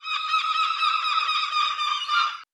Seagull 003.wav